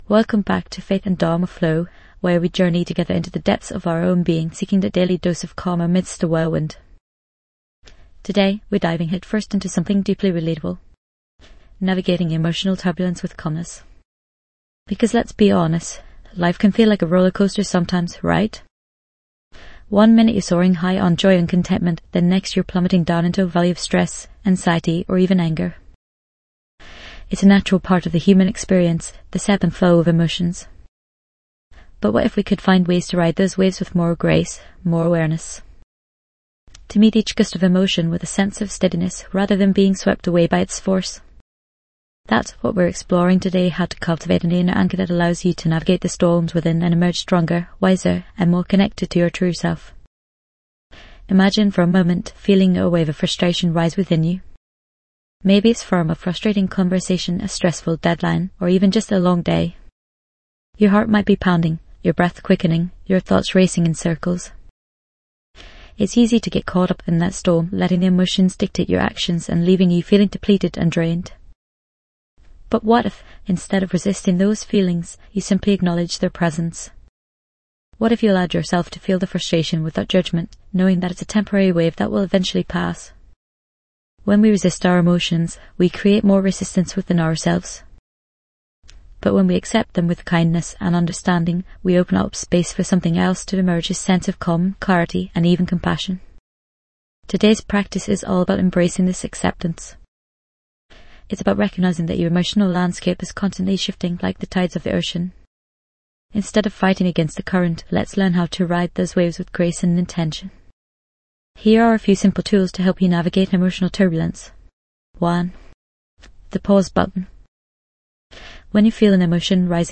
This podcast offers guided meditations, insightful reflections, and inspiring stories interwoven with wisdom from various faith and dharma traditions. Each episode is a sanctuary for your mind, helping you cultivate stillness, reduce anxiety, and connect with a deeper sense of purpose.